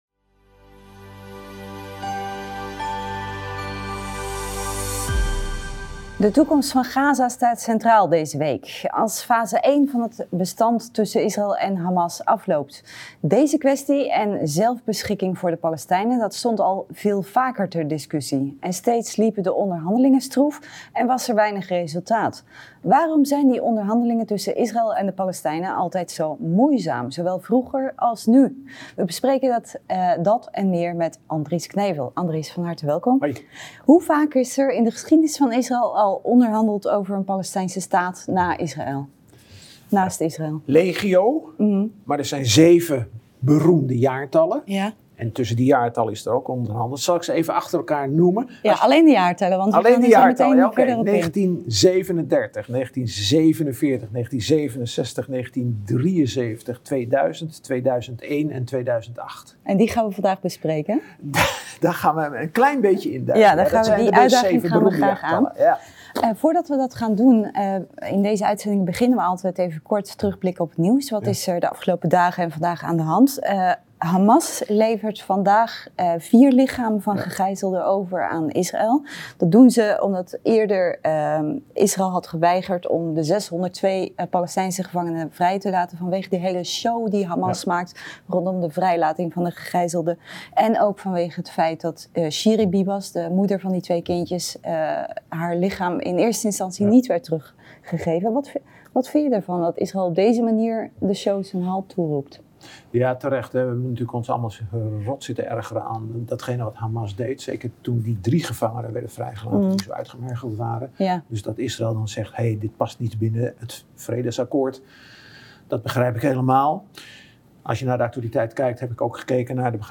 Waarom is dit er nooit van gekomen, en waar lag dat aan? Dat bespreken we met Andries Knevel.